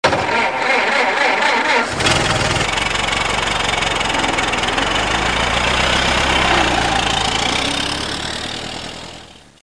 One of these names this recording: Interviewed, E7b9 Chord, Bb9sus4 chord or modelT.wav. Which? modelT.wav